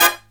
HIGH HIT05-L.wav